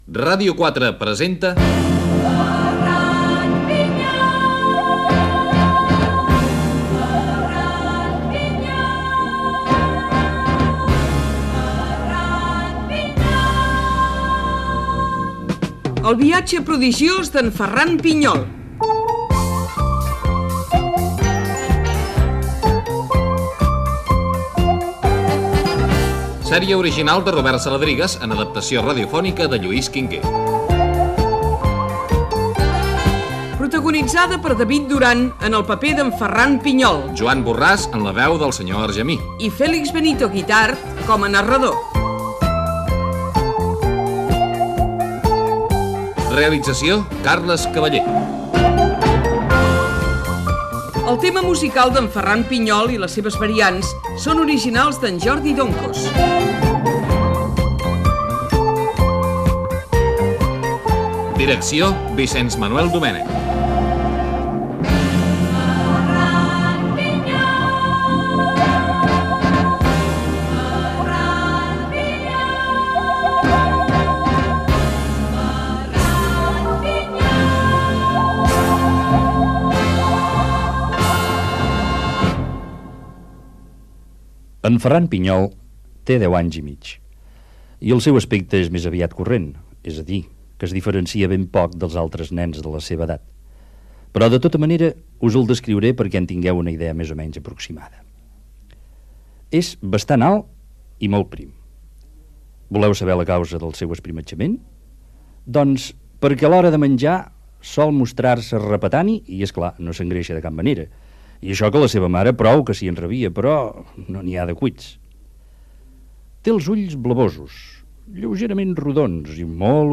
Careta del programa i primers minuts del primer episodi ,en el que es descriu el jove Ferran Pinyol.
Ficció
FM